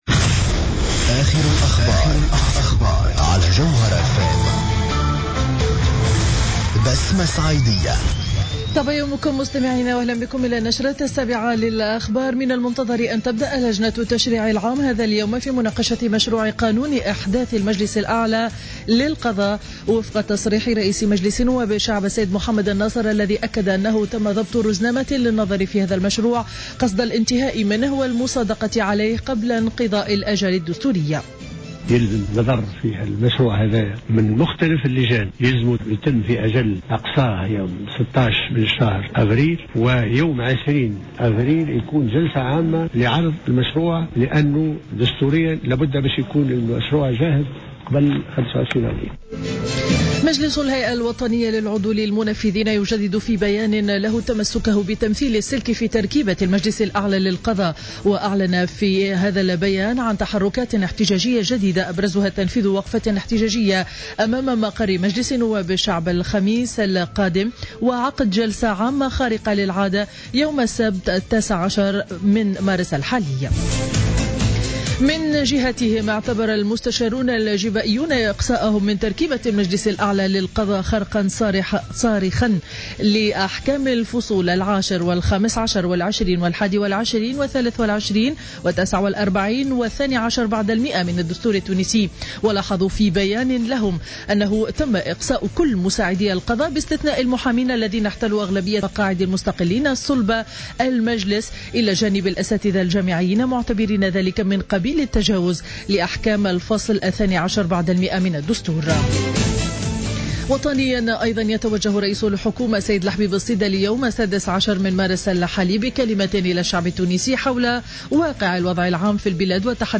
نشرة أخبار السابعة صباحا ليوم الاثنين 16 مارس 2015